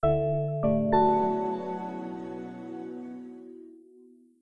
Windows X6 Startup.wav